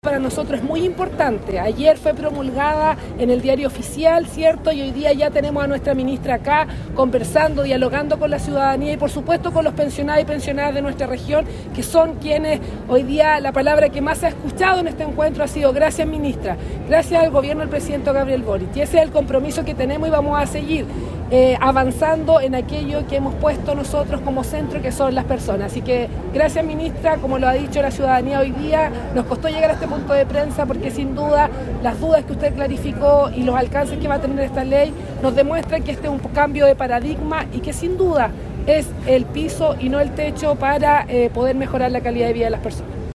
Este jueves se realizó un encuentro ciudadano en Concepción, en el contexto de la reciente promulgación de la Ley de Reforma de Pensiones, con la participación de la ministra del Trabajo y Previsión Social, Jeannette Jara, junto a otras figuras políticas de la región.